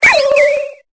Cri de Funécire dans Pokémon Épée et Bouclier.